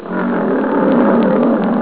Lion
LION.wav